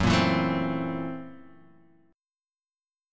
Gb7#9b5 chord